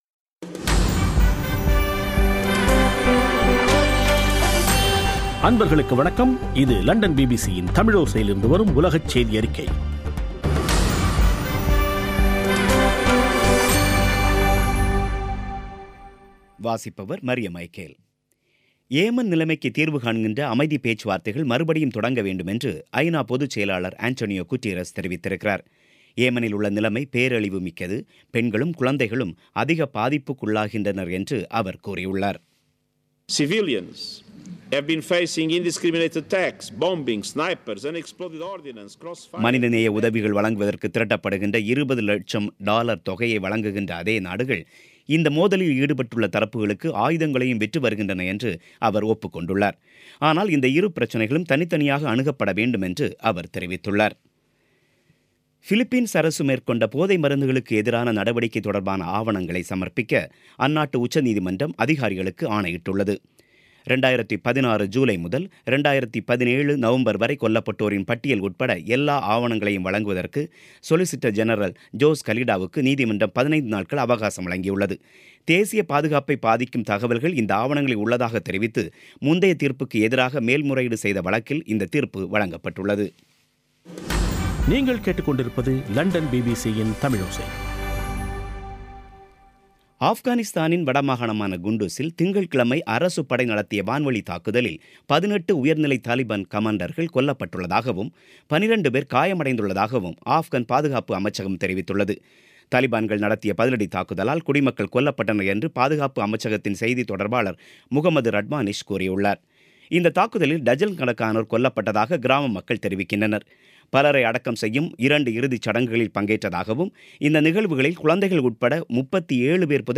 பிபிசி தமிழோசை செய்தியறிக்கை (03/04/2018)